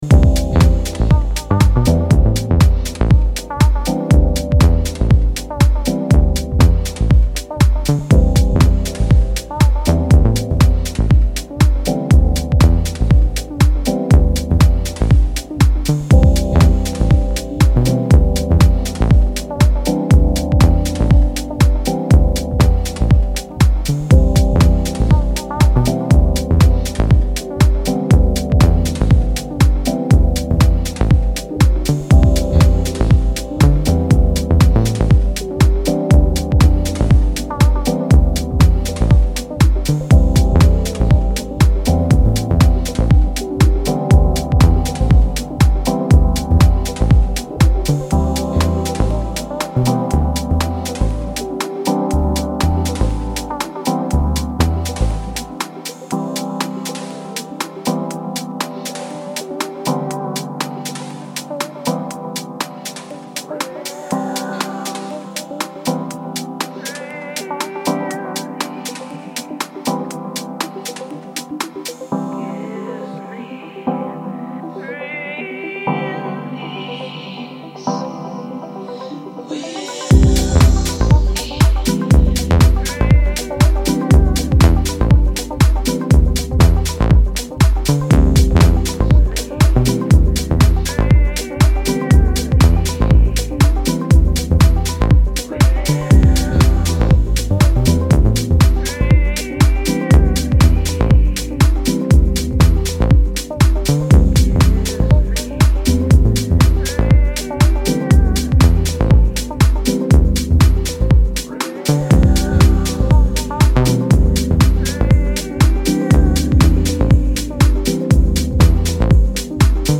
The release fuses jazzy chords, deep synthy bass lines
and catchy vocals to create the French class sound so many